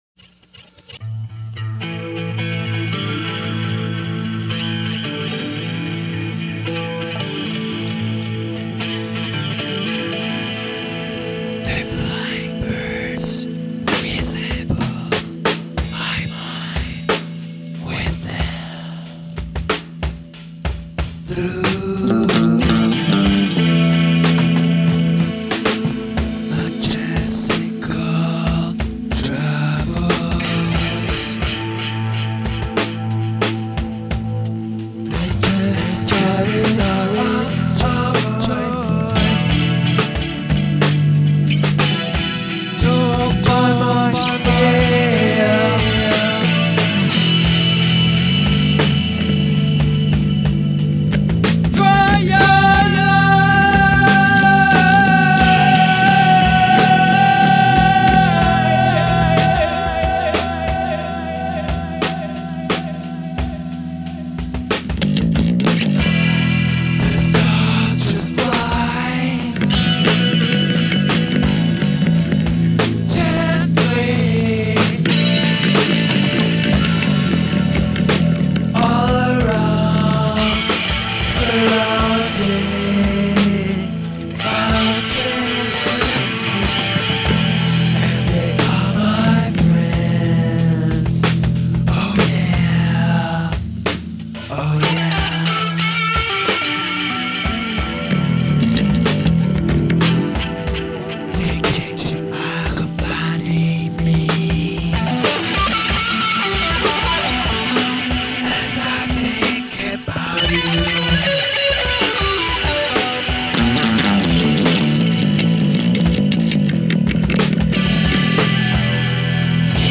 drums
electric guitar
I then overdubbed some Chapman Stick